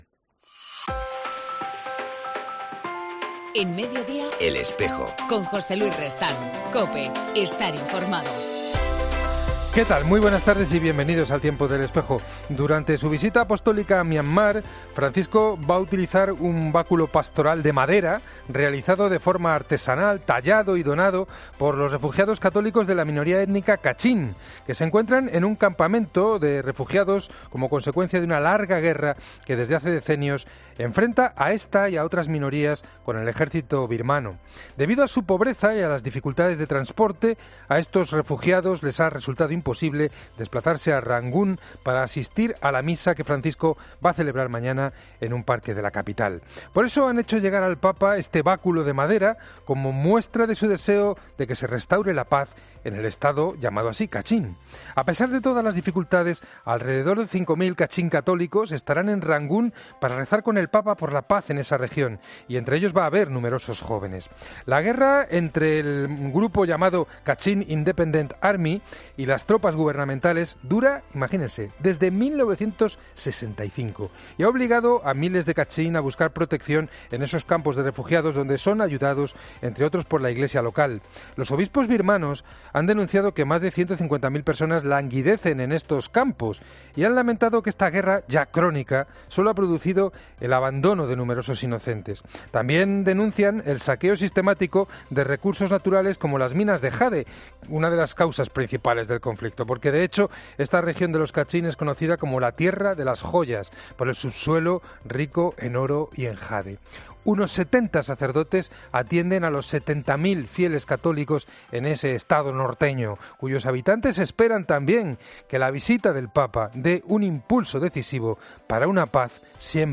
En El Espejo del 28 de noviembre hablamos con